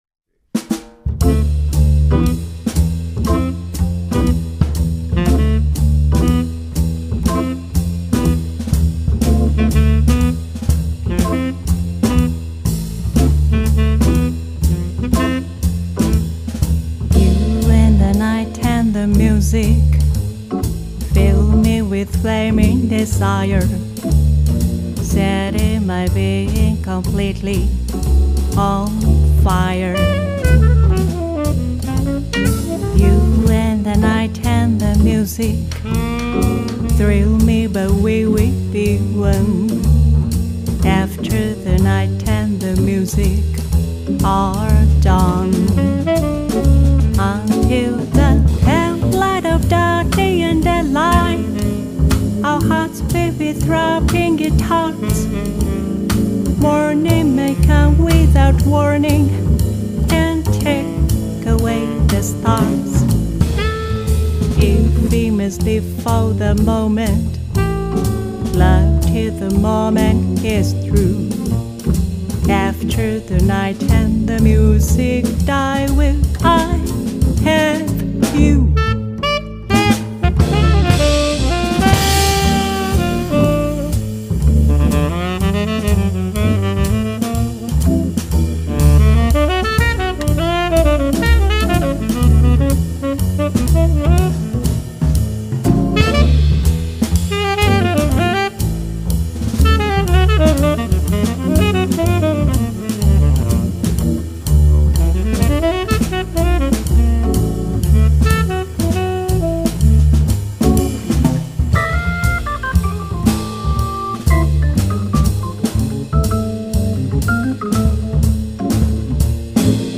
トラック 9 ジャンル: Hardcore